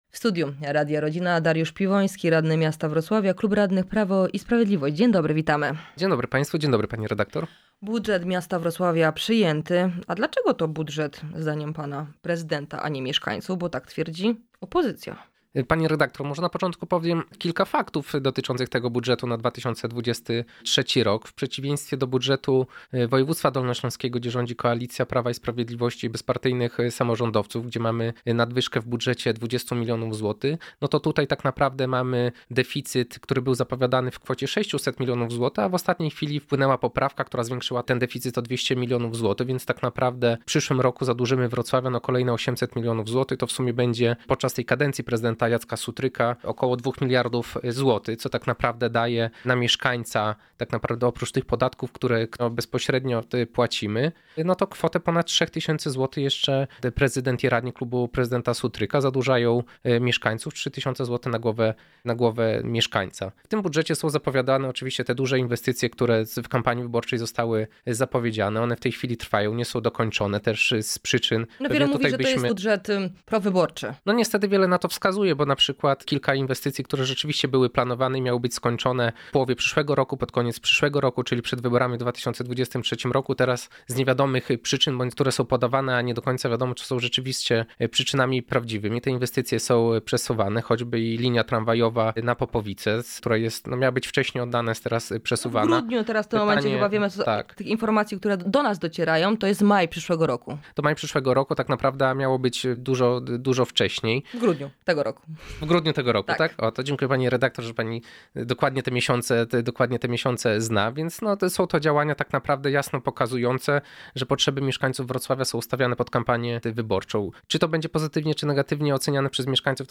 Z radnym Dariuszem Piwońskim rozmawiamy, dlaczego zagłosowali przeciw i czy jest to dobry budżet dla Wrocławia.